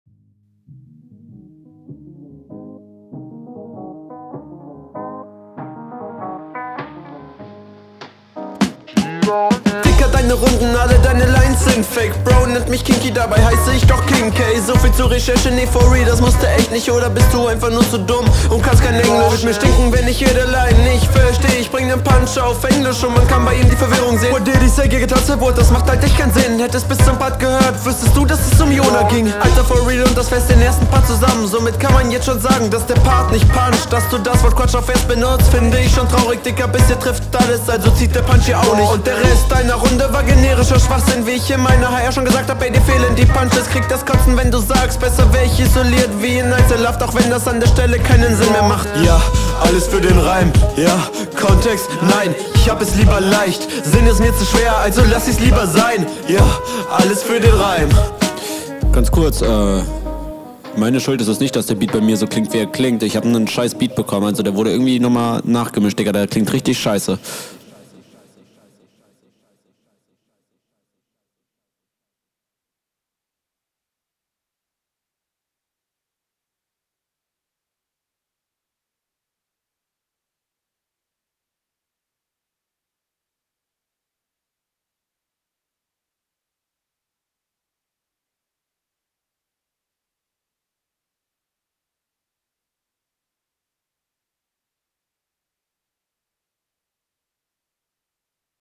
Würde im Mix nicht so viel Distortion auf die Stimme packen, das ist ja schließlich …